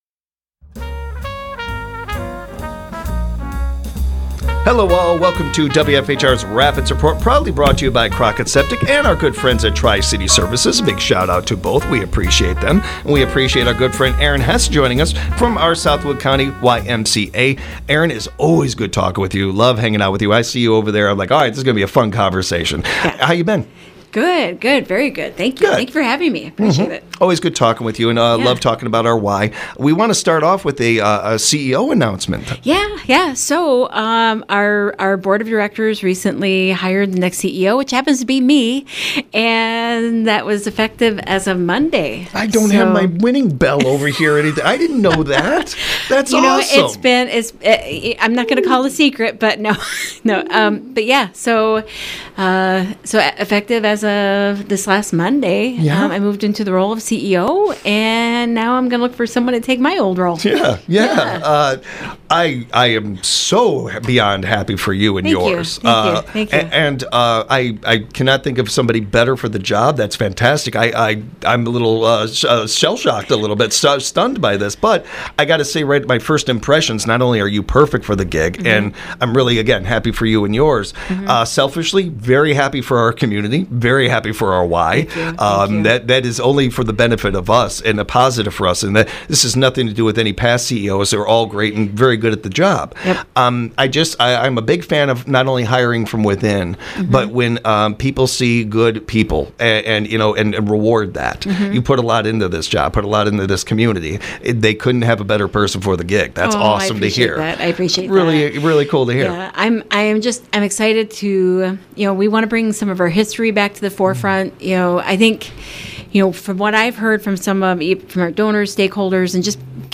Special thanks to Tri-City Services for sponsoring interviews with the SWC YMCA.